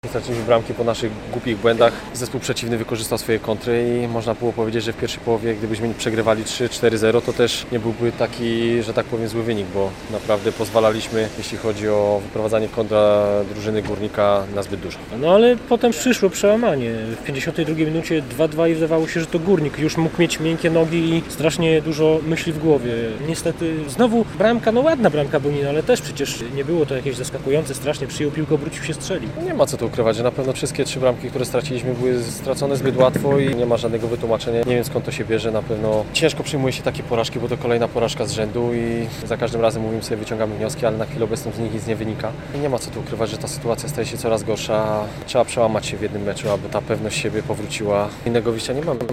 po meczu rozmawiał